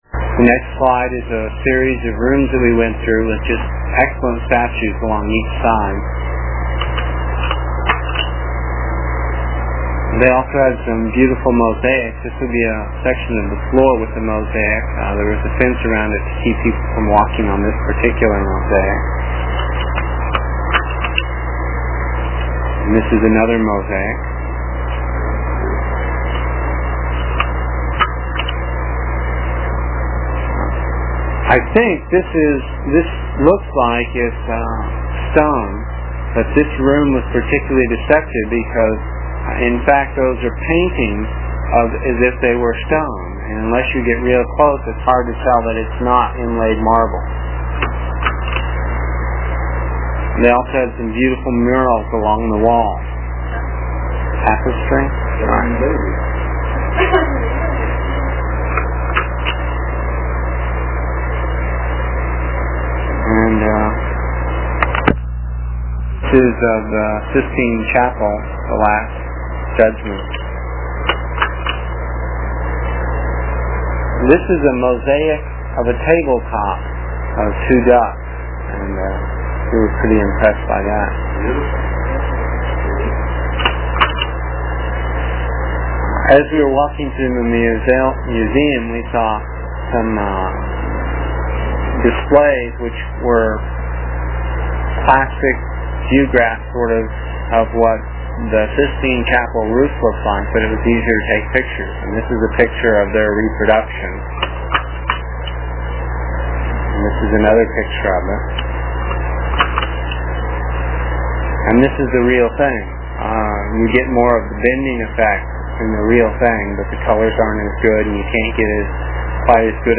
voice description of each slide that you can listen to while looking at the slides. It is from the cassette tapes we made almost thirty years ago. I was pretty long winded (no rehearsals or editting and tapes were cheap) and the section for this page is about three minutes and will take about a minutes to download with a dial up connection.